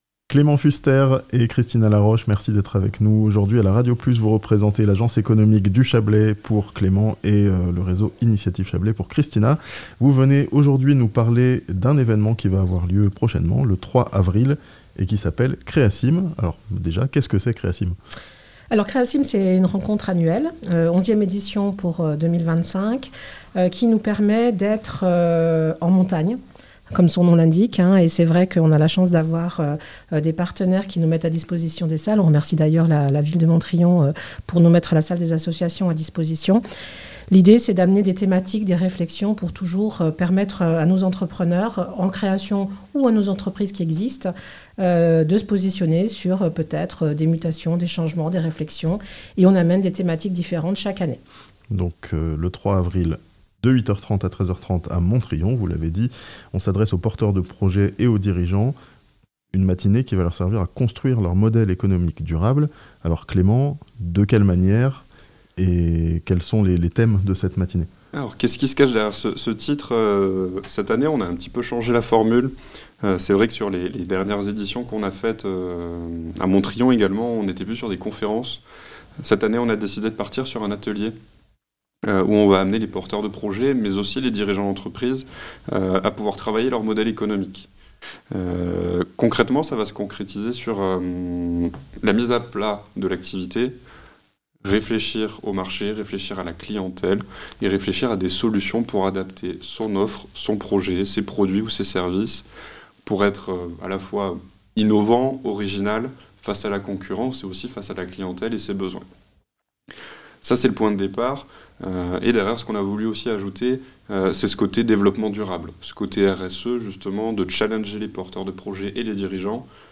Les dirigeants d'entreprises et porteurs de projets ont RDV à Montriond le 3 avril (interview)